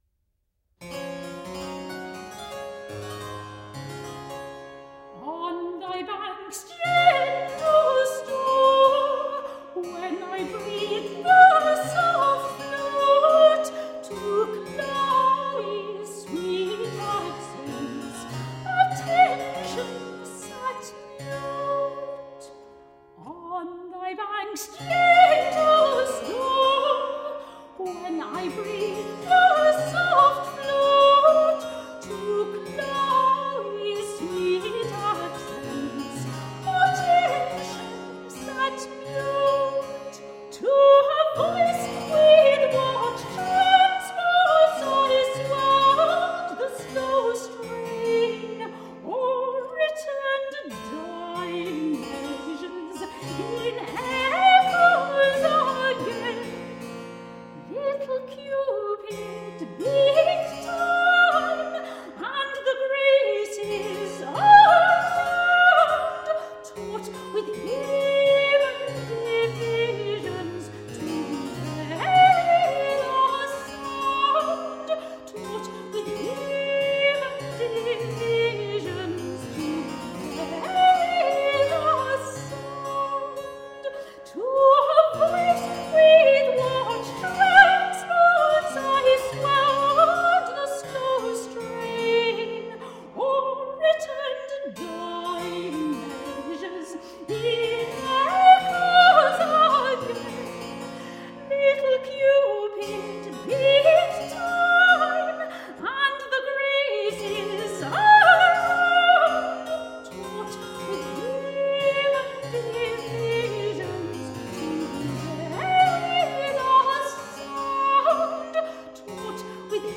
performed by the radiant clear soprano
Classical, Renaissance, Baroque, Classical Singing